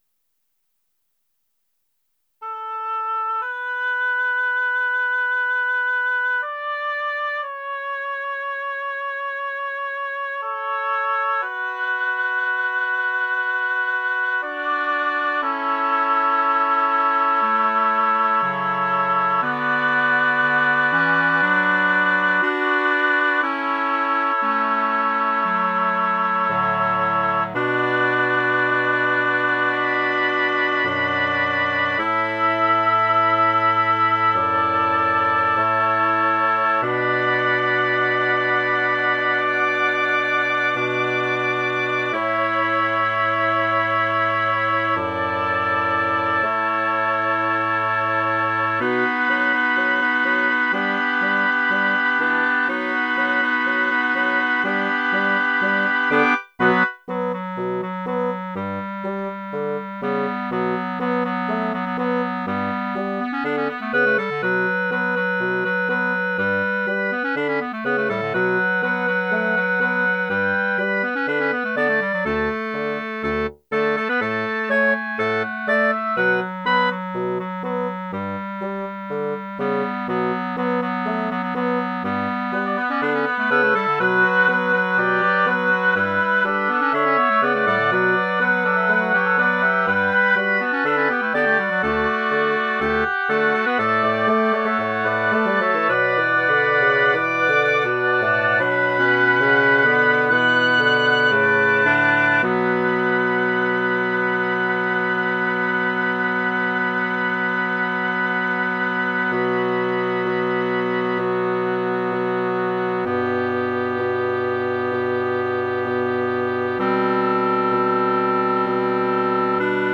Title Woodwind Sextet Opus # 145 Year 0000 Duration 00:03:04 Self-Rating 3 Description A pleasant little thing. mp3 download wav download Files
Clarinet, Woodwinds Plays
145 Woodwind Sextet.wav